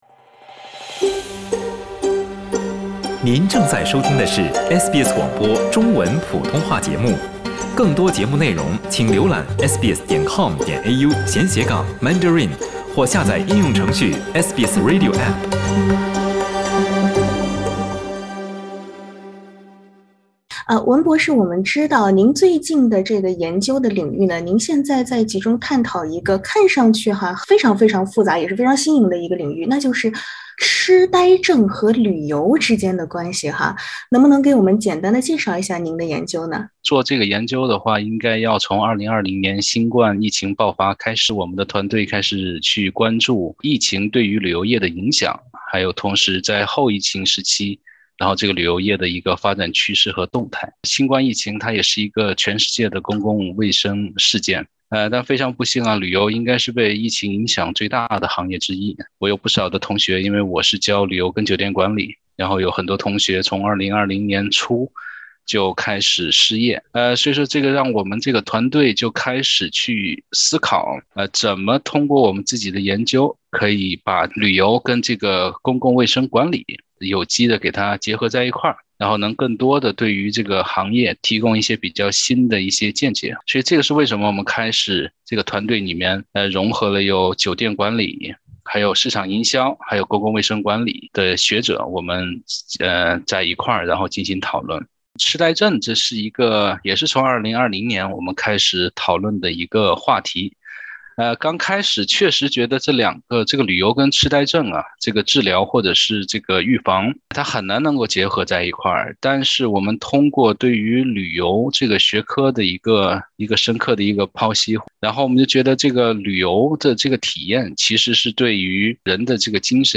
您想过吗，旅游和痴呆症之间有没有潜在联系？一个由旅游和公共卫生专家组成的跨国研究团队探讨了这个问题。（点击上图收听采访）